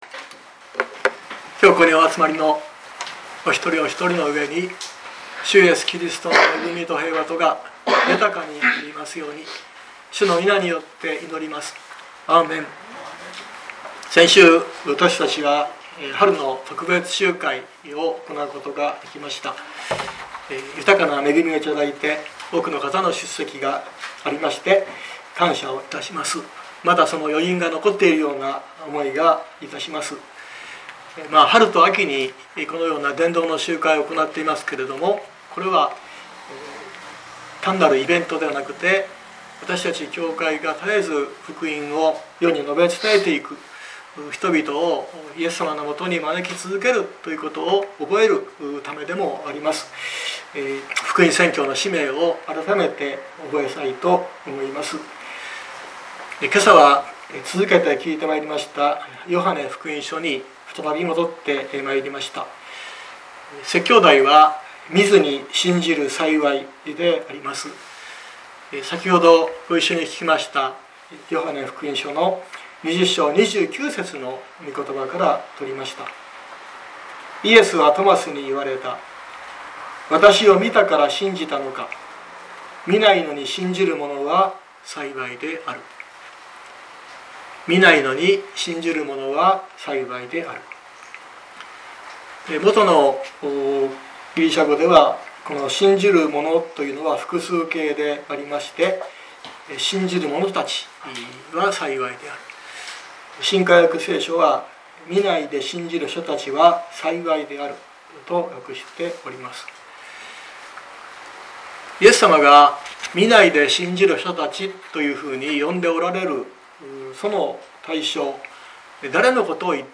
熊本教会。説教アーカイブ。